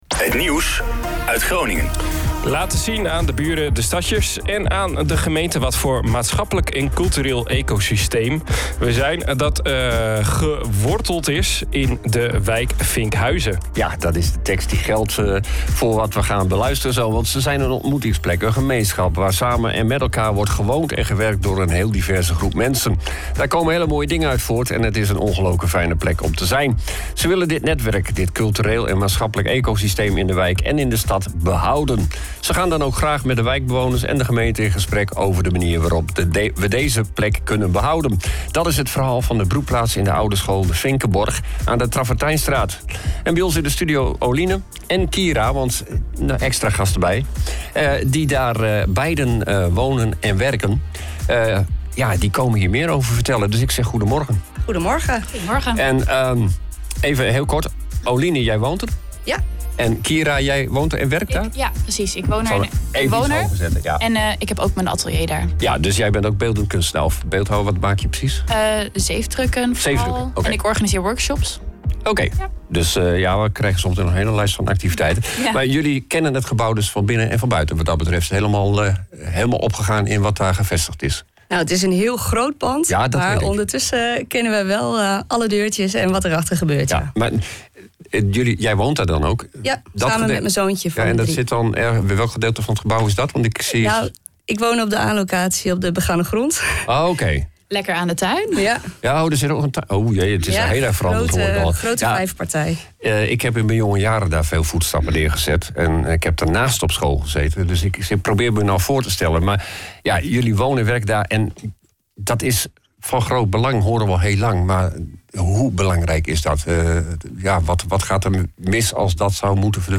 ROnAR Light 4.4.0.0 De OOG Ochtendshow is een vrolijke radioshow met het lokale nieuws, de beste muziek en natuurlijk het weer en beluister je iedere werkdag van 07:00 – 09:00 uur op OOG Radio. Hier vind je alle interviews van de OOG Ochtendshow.